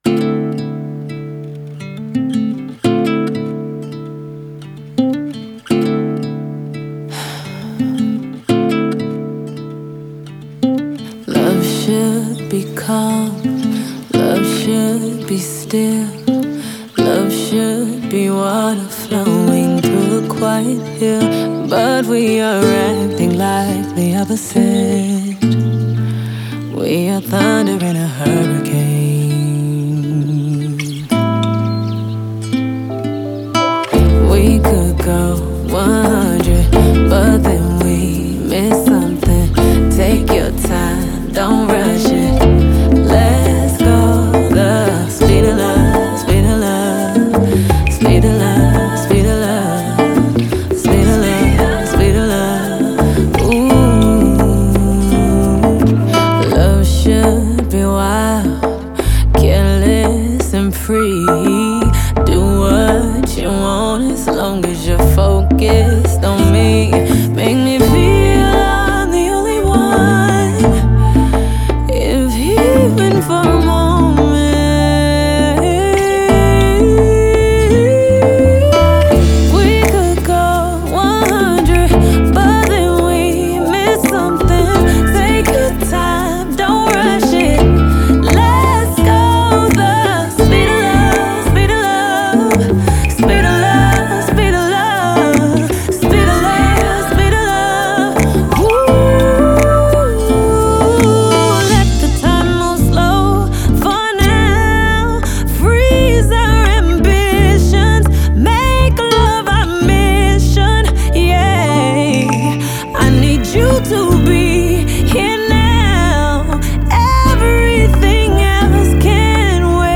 зажигательная R&B-песня